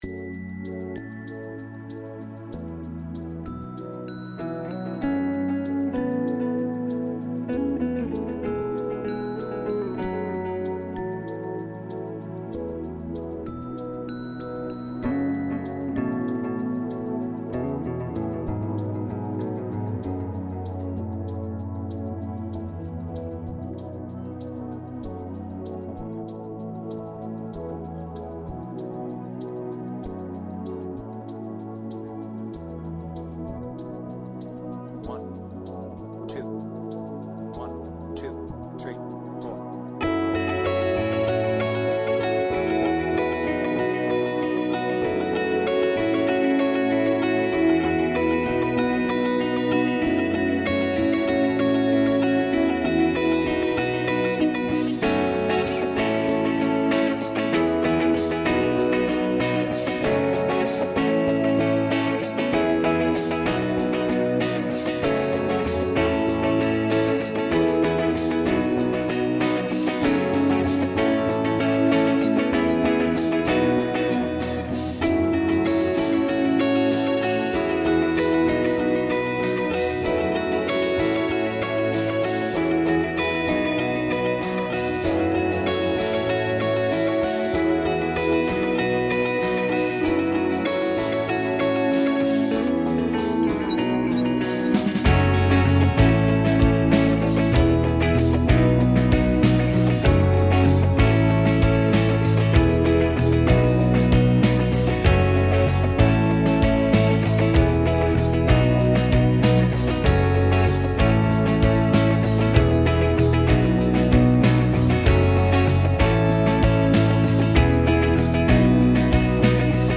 BPM : 146
With Vocals